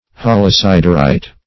Meaning of holosiderite. holosiderite synonyms, pronunciation, spelling and more from Free Dictionary.
Search Result for " holosiderite" : The Collaborative International Dictionary of English v.0.48: Holosiderite \Hol`o*sid"er*ite\, n. [Holo + siderite.]